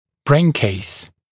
[breɪn keɪs][брэйн кейс]черепная коробка